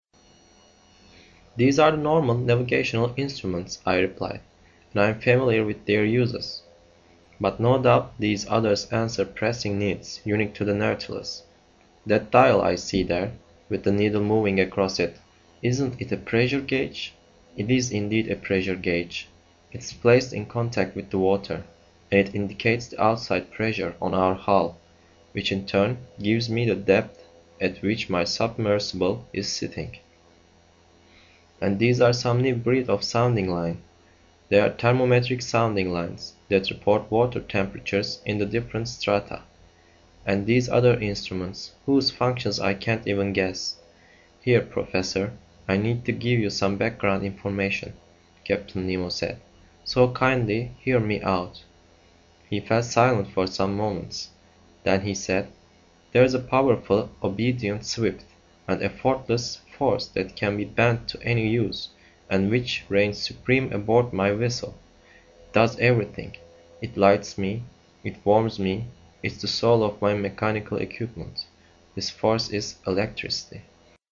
英语听书《海底两万里》第168期 第12章 一切都用电(1) 听力文件下载—在线英语听力室
在线英语听力室英语听书《海底两万里》第168期 第12章 一切都用电(1)的听力文件下载,《海底两万里》中英双语有声读物附MP3下载